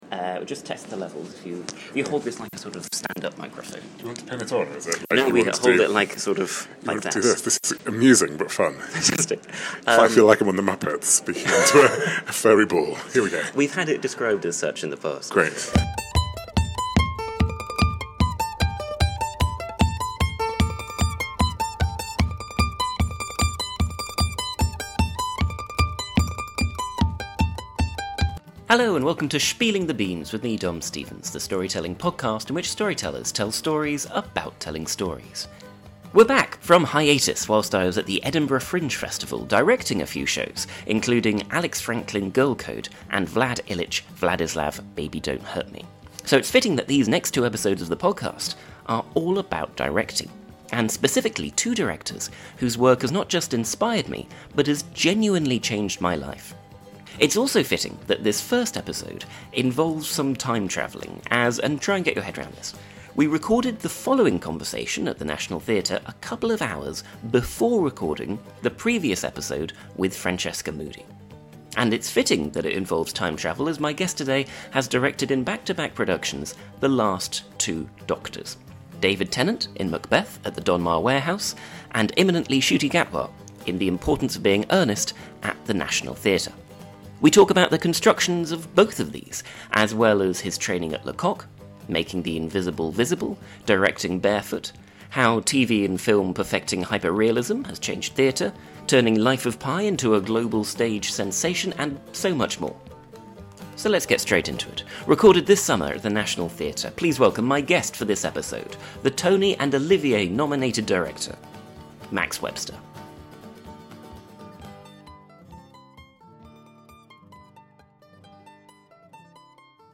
We discuss the construction of these shows, his training at La Coq, making the invisible visible, directing barefoot, how film and tv perfecting hyper realism has changed theatre, and so much more. Recorded at The National Theatre in June 2024.